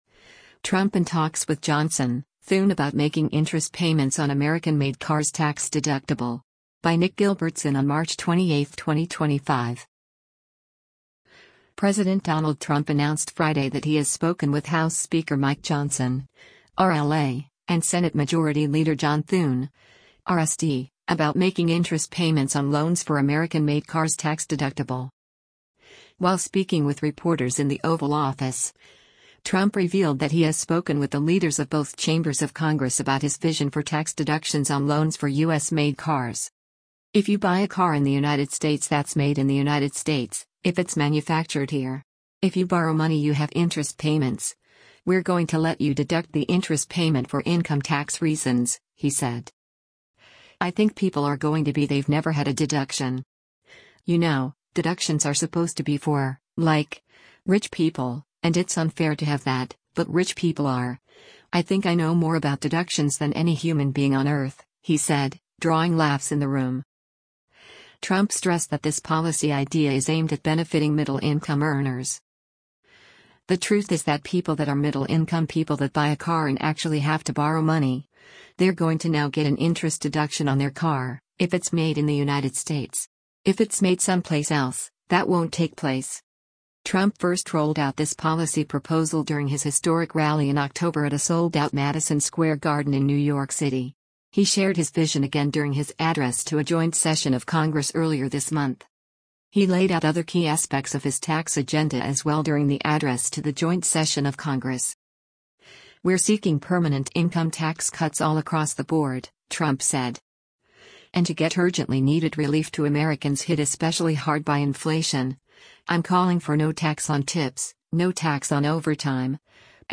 While speaking with reporters in the Oval Office, Trump revealed that he has spoken with the leaders of both chambers of Congress about his vision for tax deductions on loans for U.S.-made cars.
“I think people are going to be–they’ve never had a deduction. You know, deductions are supposed to be for, like, rich people, and it’s unfair to have that, but rich people are – I think I know more about deductions than any human being on Earth,” he said, drawing laughs in the room.